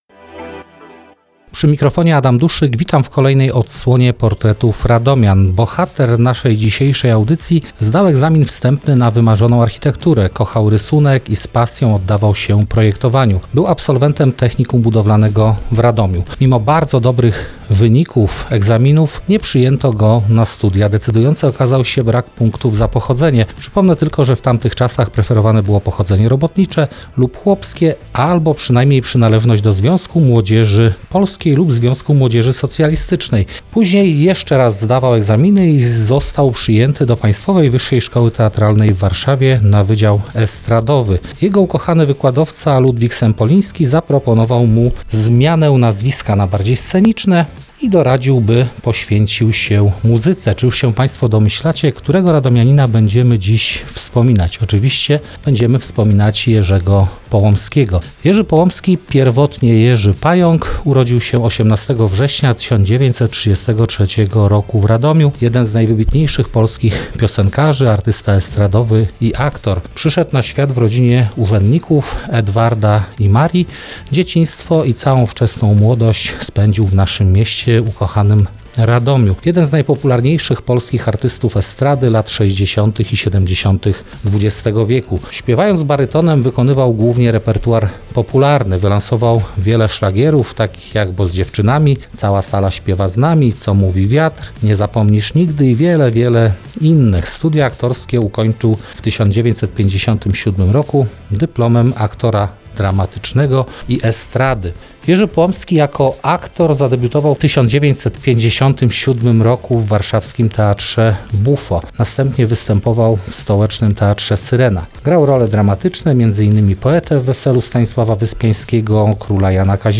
Piosenkarz, aktor i artysta estradowy Jerzy Połomski jest dzisiejszym bohaterem programu „Portrety Radomian”. Jego sylwetkę przedstawił historyk